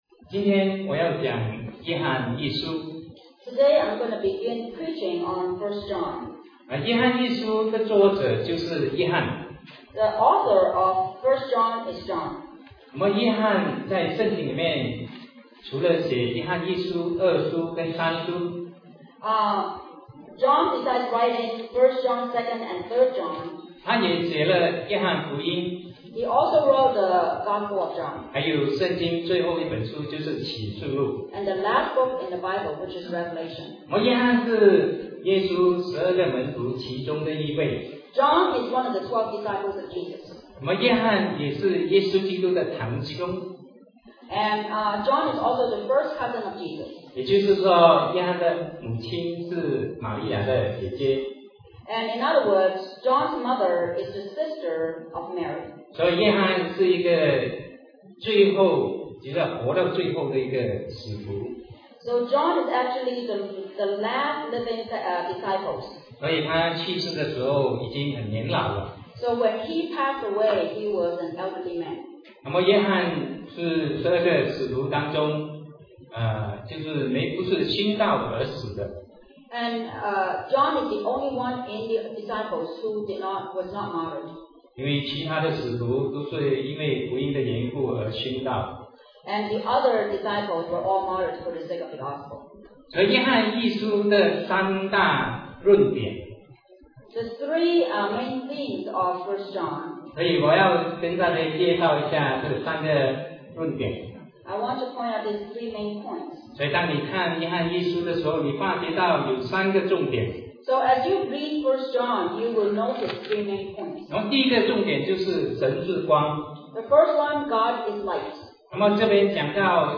Sermon 2009-07-19 Christ Came that We Might have Life